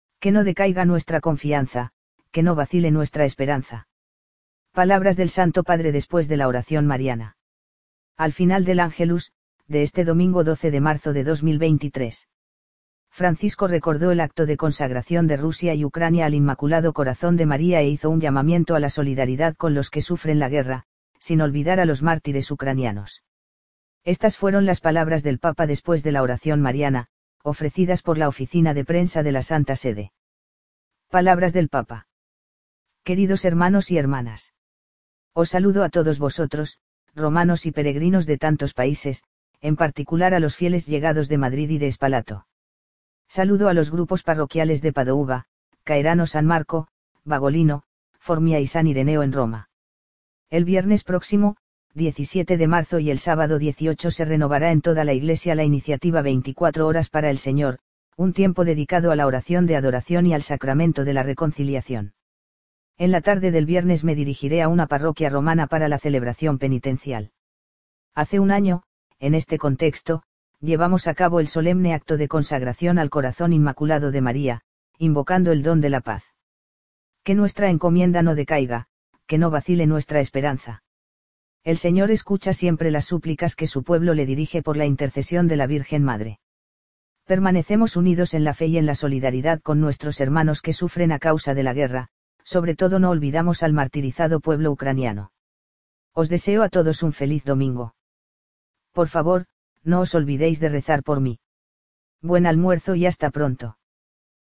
Palabras del Santo Padre después de la oración mariana